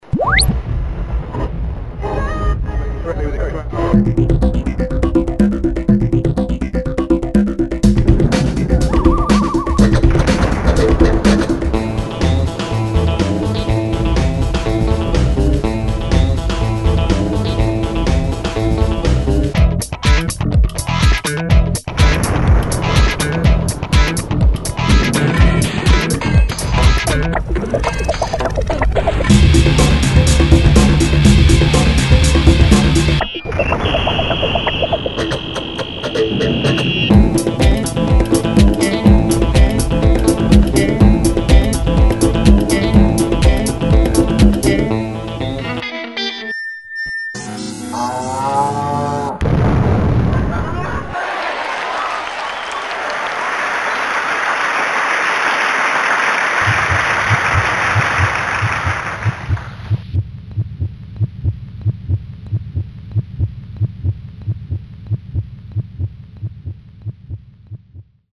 dance/electronic
Made entirely from sounds lifted from teh_intarwebs.
Experimental
Rock & Roll